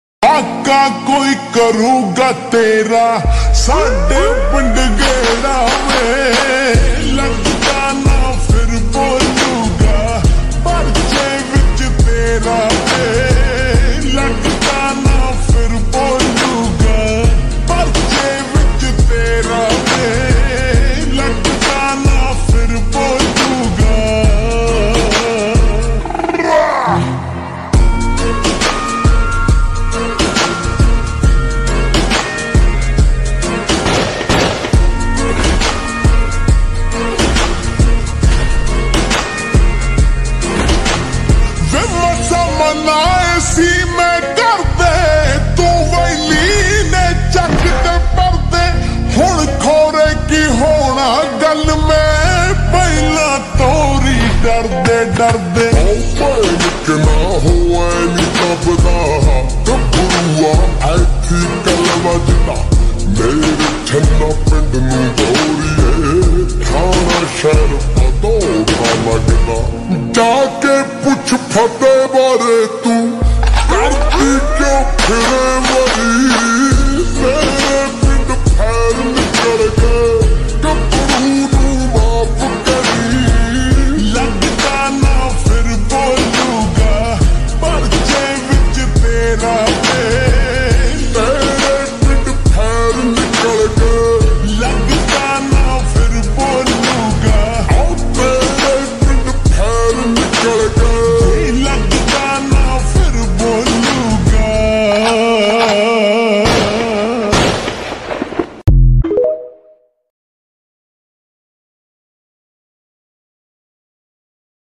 slowandreverb